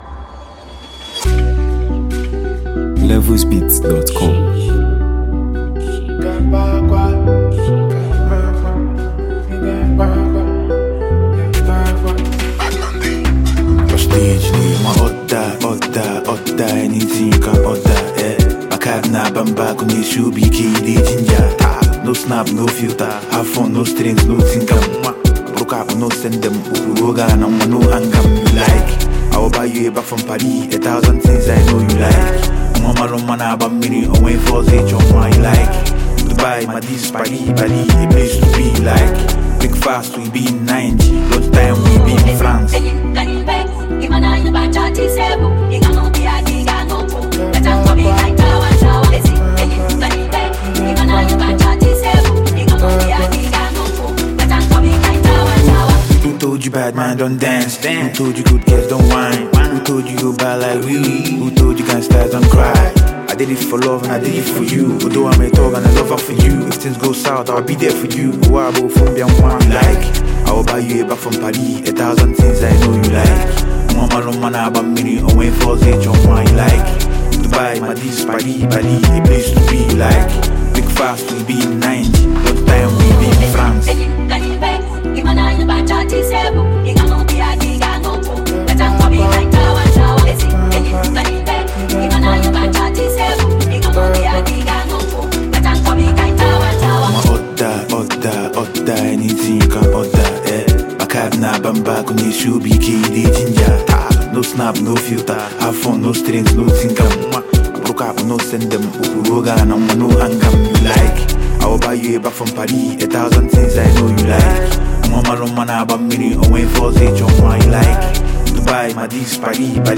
Known for his bold delivery and culturally rich sound